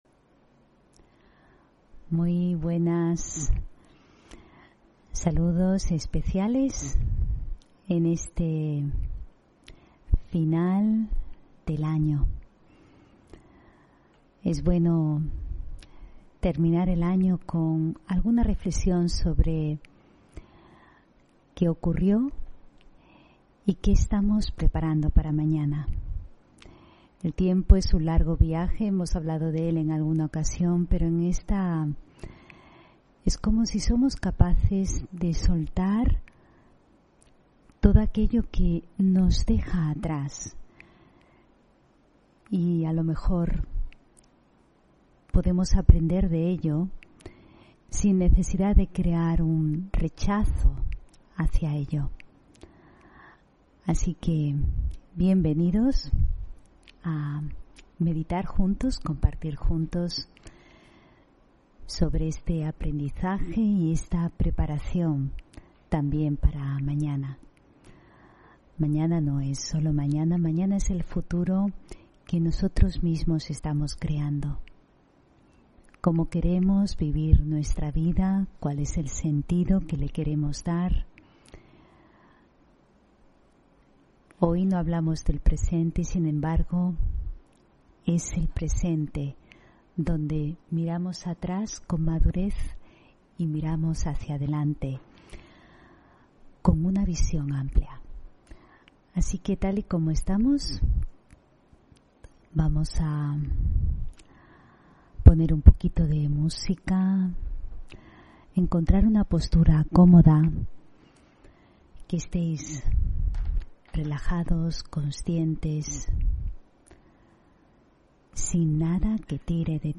Meditación y conferencia: Soltar el pasado y aprender para el futuro (8 Diciembre 2021)